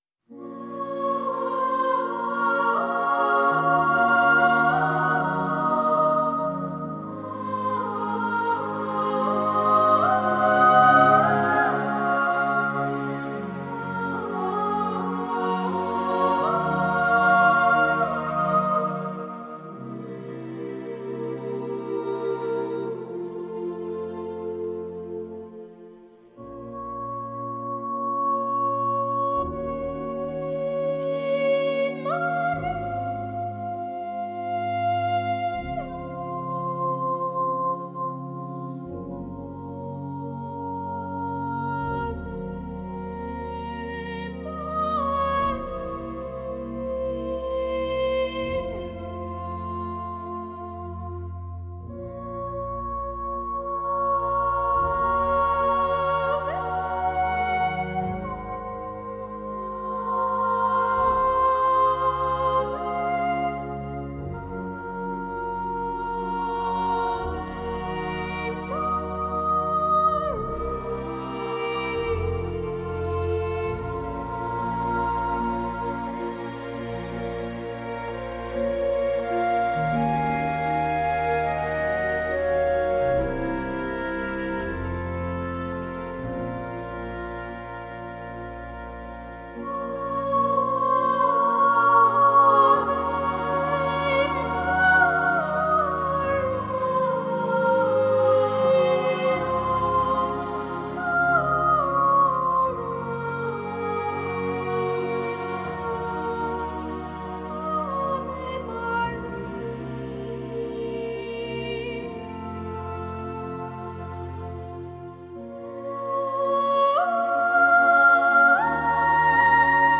London boy choir